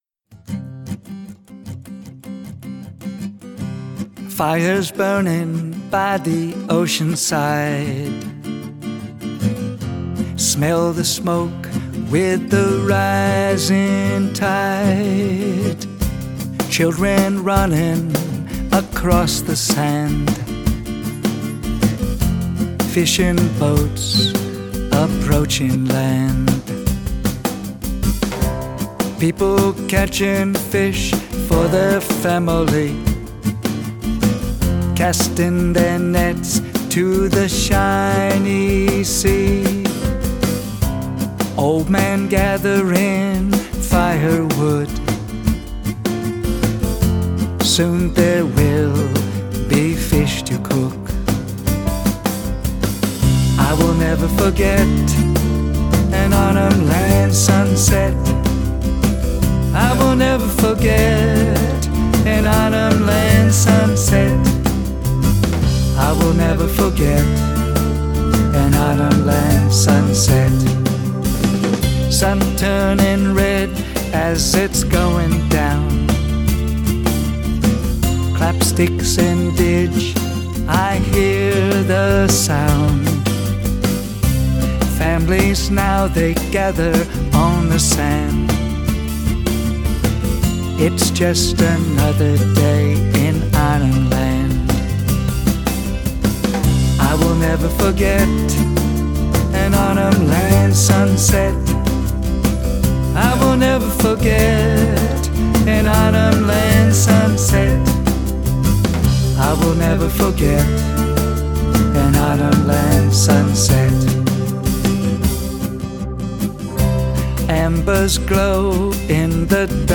Vocals, Guitar
Vocals, Keyboard